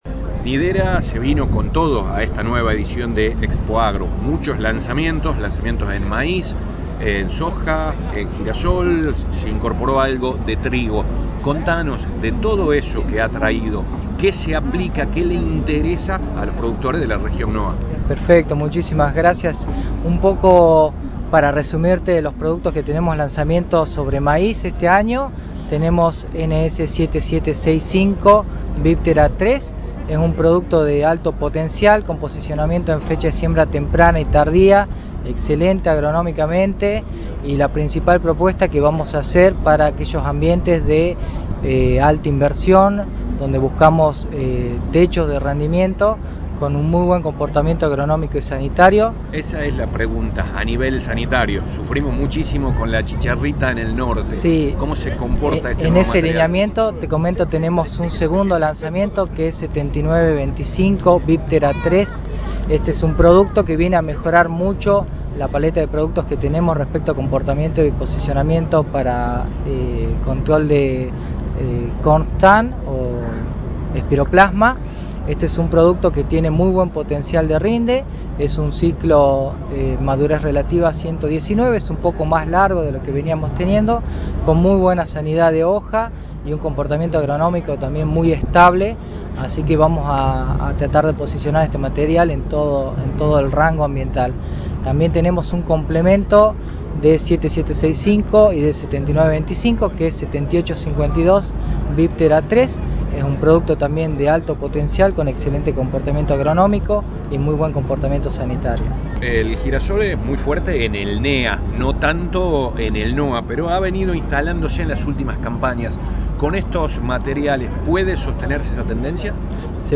En la reciente ExpoAgro 2026, la compañía semillera Nidera presentó sus nuevos desarrollos en girasol, soja, maíz y trigo.